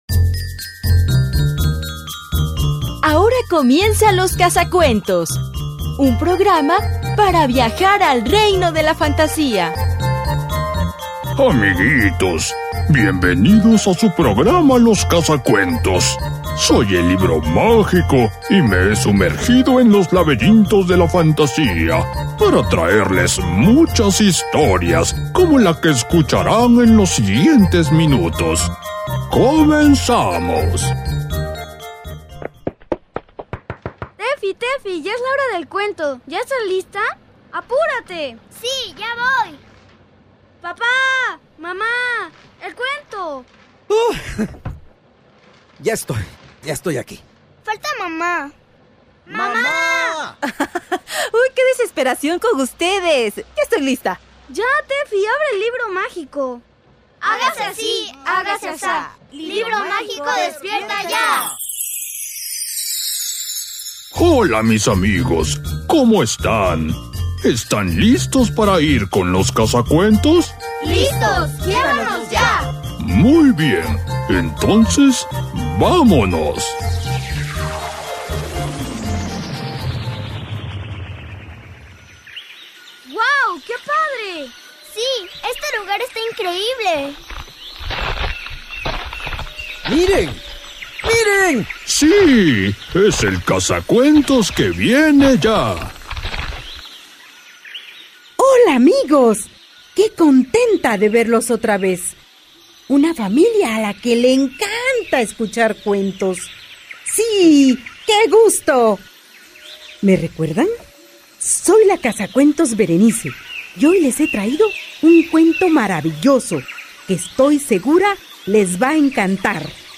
T02_03_Cuento_Las-Aventuras-de-Rigoberto.mp3